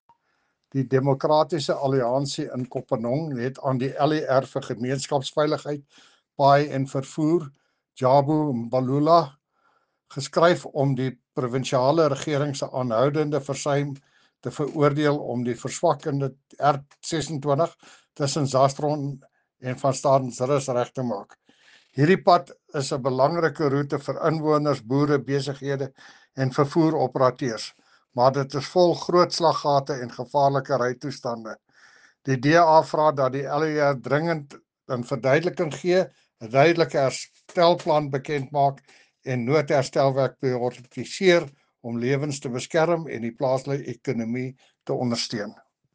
Issued by Cllr. Jacques van Rensburg – DA Councillor Kopanong Municipality
Afrikaans soundbites by Cllr Jacques van Rensburg and Sesotho soundbite by David Masoeu MPL.